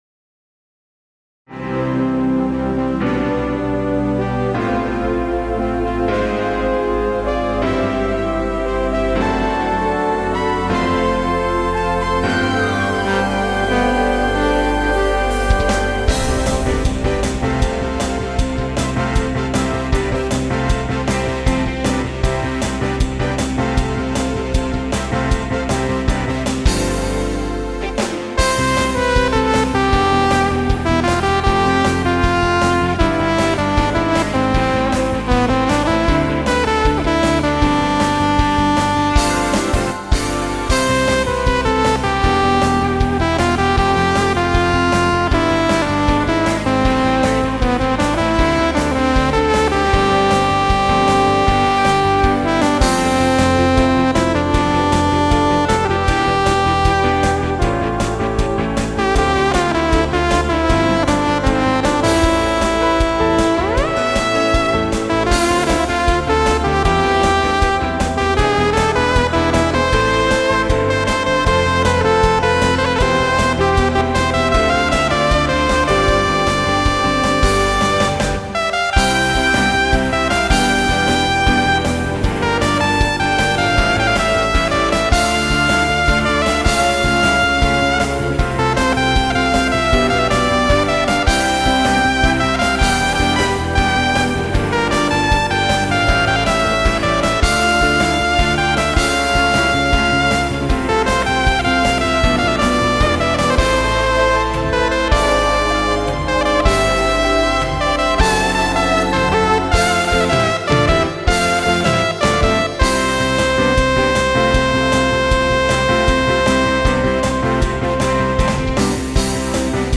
EWI4000S 사운드 데모
Akai EWI4000S (Patch No.1 : Singing Saw) - Zoom RFX2000